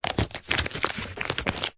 Звуки наклеек
Большую наклейку превращаем в клубок мусора